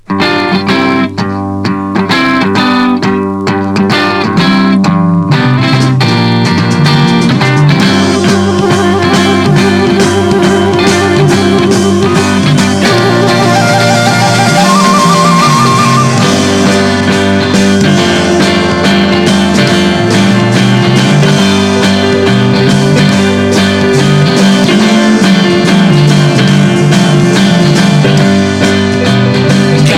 Heavy progressif Deuxième 45t retour à l'accueil